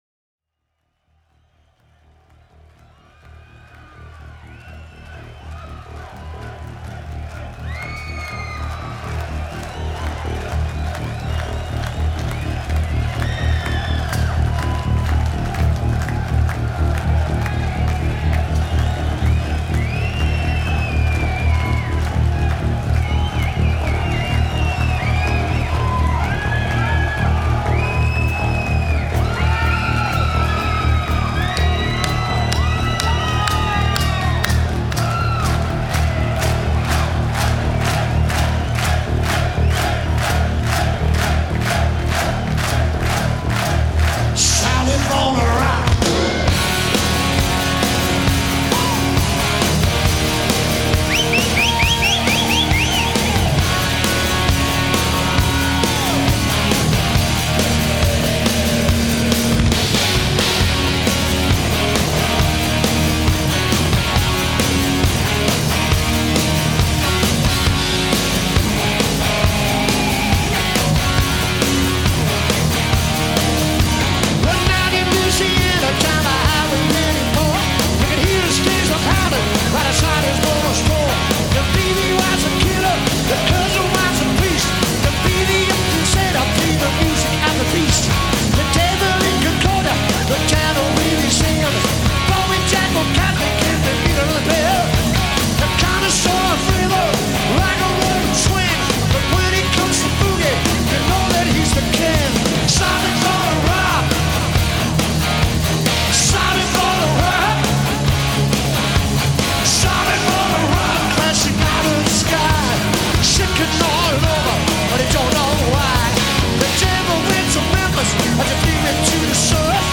It sounds great and the band performs well.
In fact, he makes it work for him.